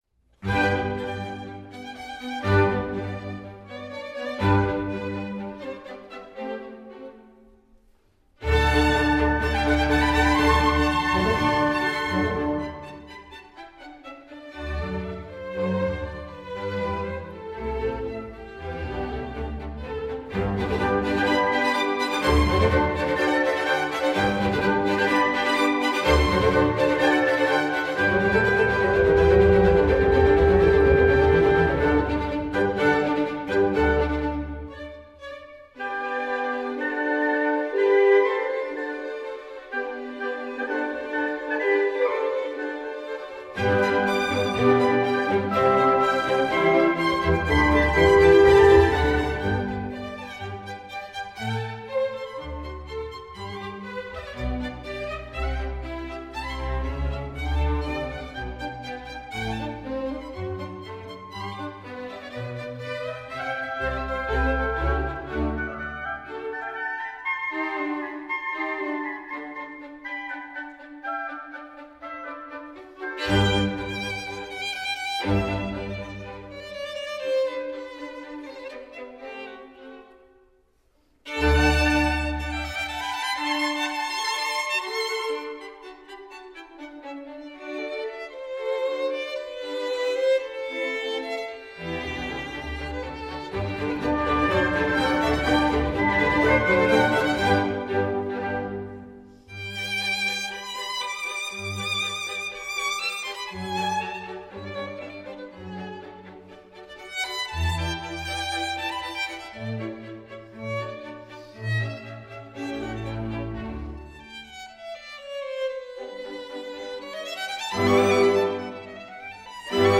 La violinista lettone in concerto con l’OSI